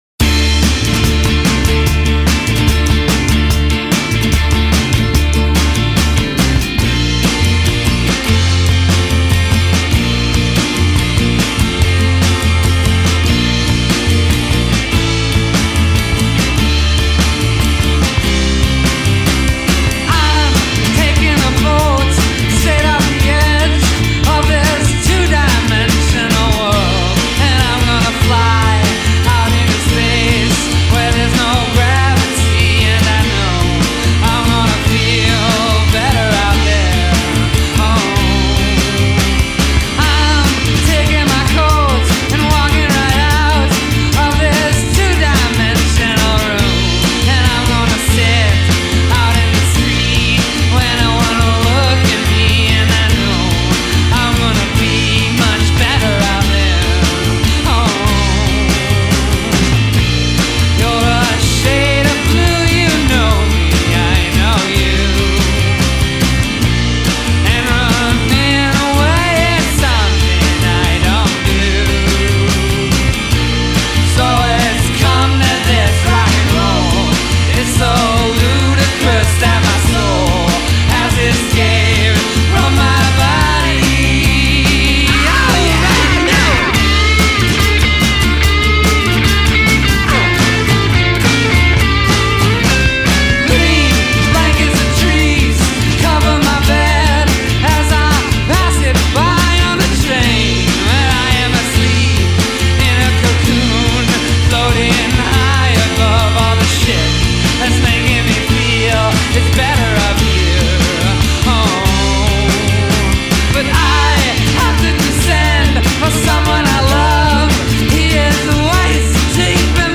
tie together down-home style tunes with angst-y